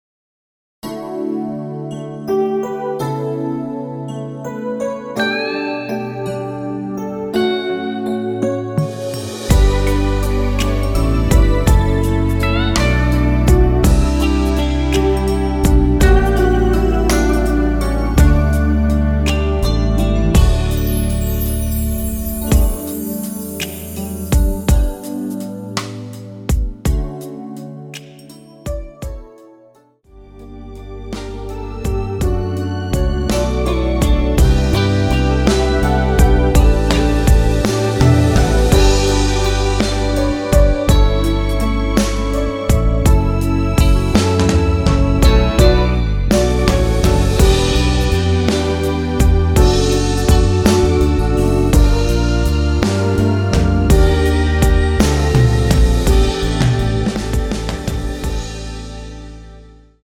원키(2절 삭제) MR입니다.(미리듣기 참조)
D
앞부분30초, 뒷부분30초씩 편집해서 올려 드리고 있습니다.
중간에 음이 끈어지고 다시 나오는 이유는